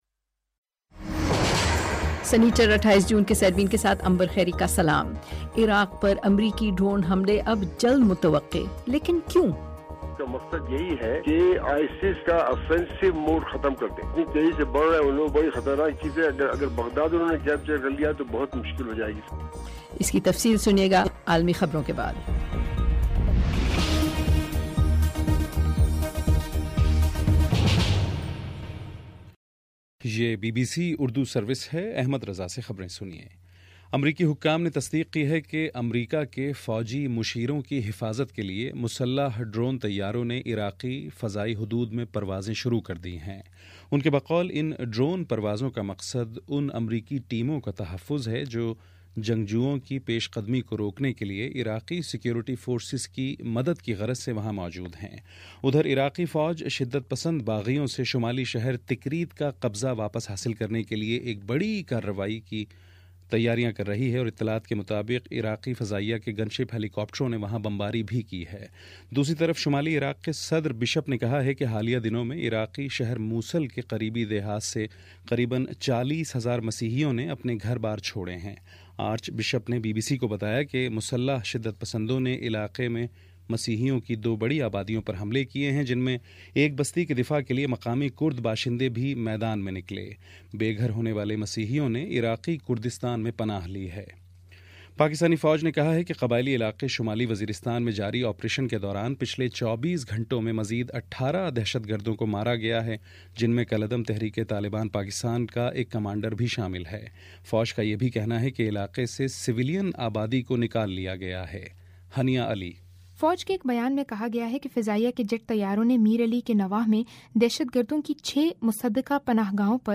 سنیچر 28 جون کا سیربین ریڈیو پروگرام